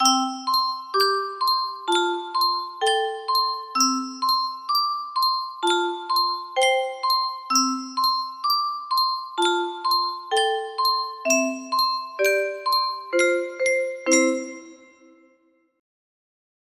Clone of Yunsheng Music Box - Peter Peter Pumpkin Eater 1778 music box melody